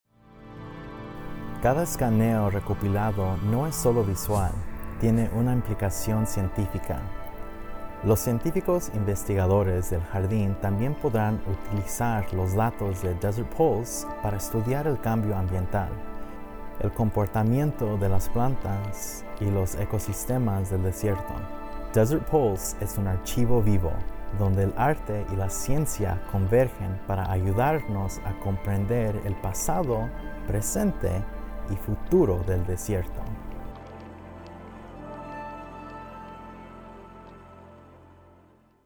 Este es un viaje sonoro autoguiado, diseñado para acompañarte mientras te desplazas entre los sitios de las instalaciones.